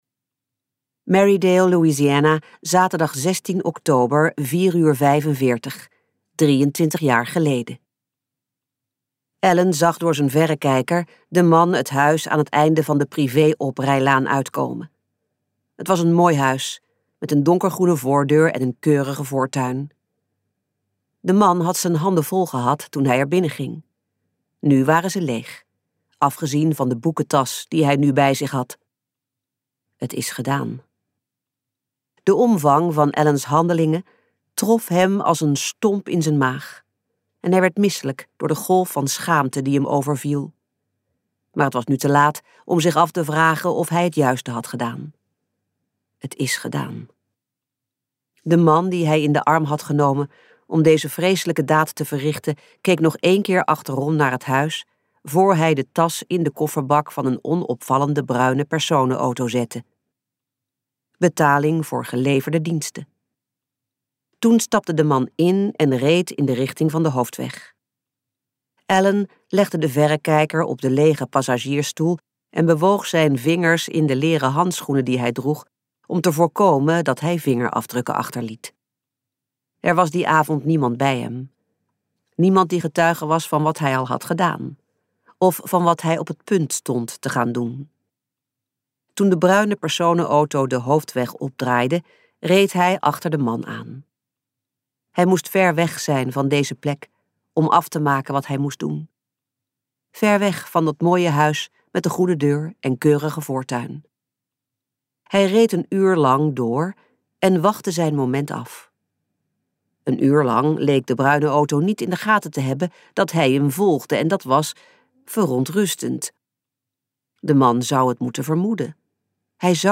Uitgeverij De Fontein | Diep verborgen luisterboek